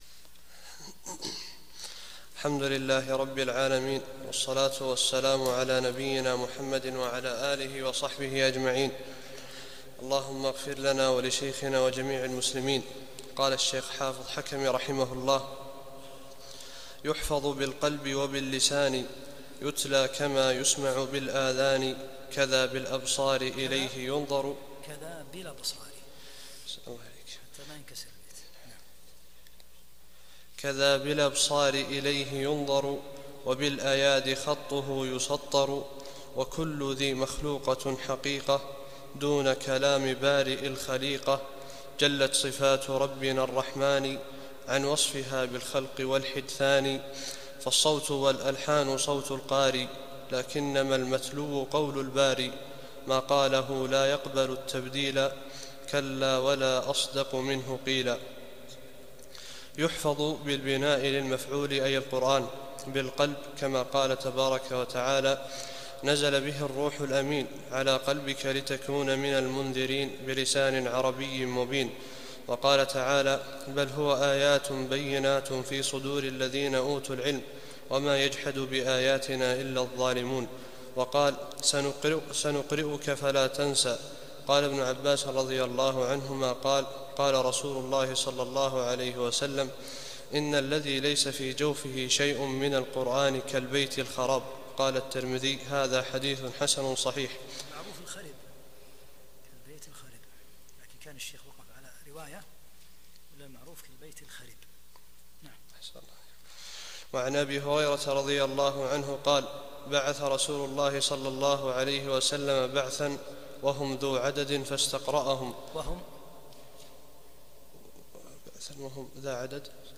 42- الدرس الثاني والأربعون